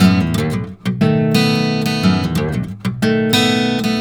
Index of /90_sSampleCDs/ILIO - Fretworks - Blues Guitar Samples/Partition G/120BARI RIFF